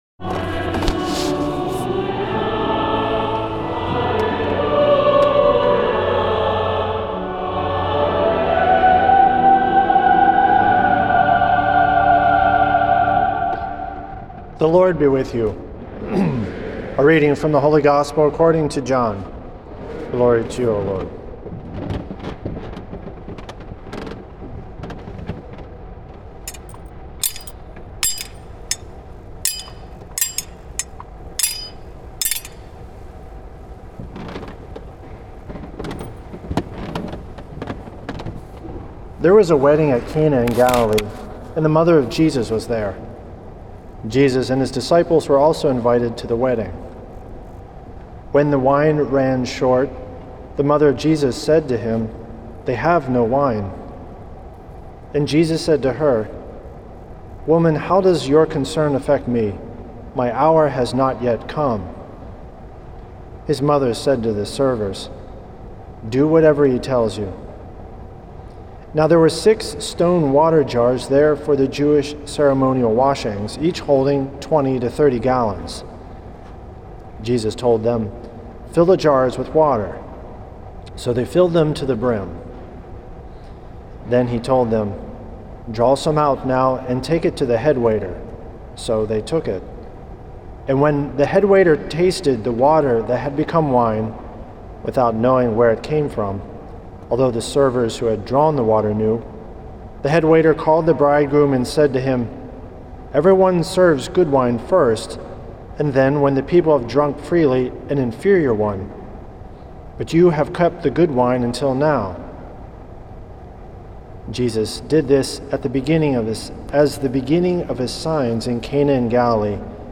Homily
at St. Patrick’s Old Cathedral in NYC on January 19th